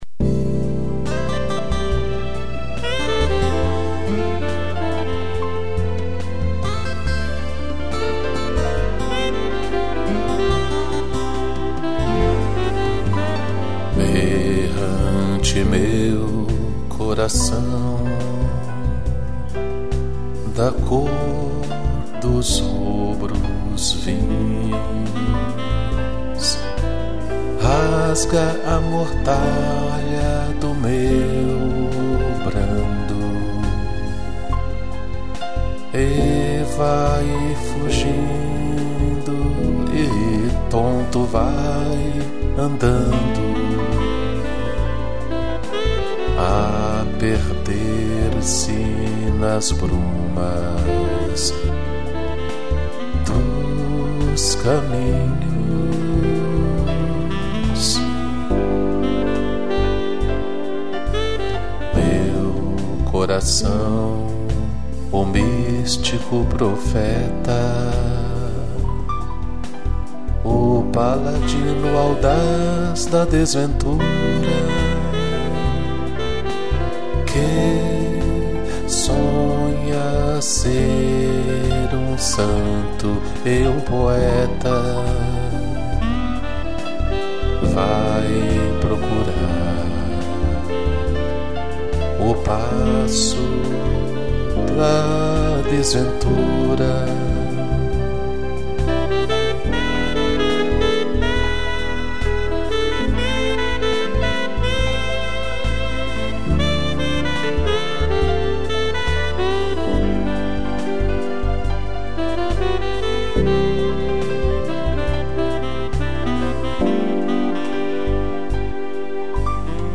piano, violino e sax